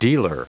Prononciation du mot dealer en anglais (fichier audio)
Prononciation du mot : dealer